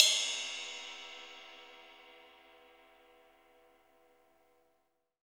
CYM ROCK 0OL.wav